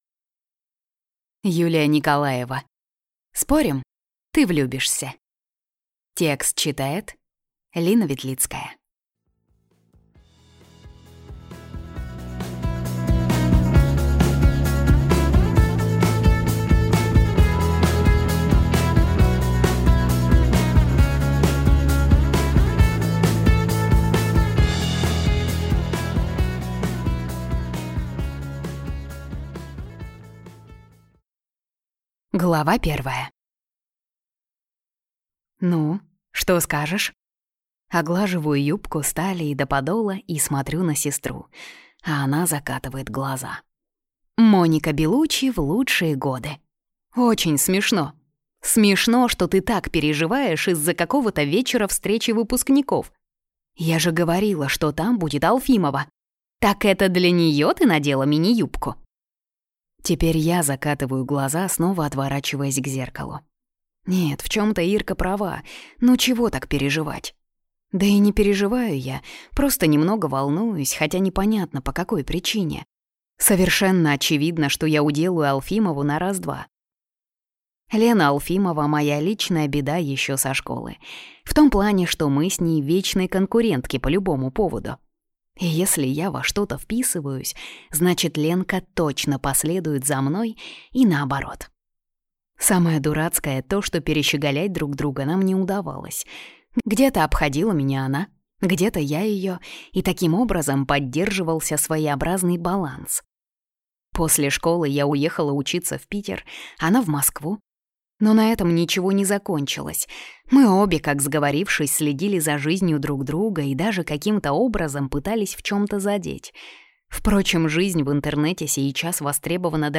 Аудиокнига Спорим, ты влюбишься?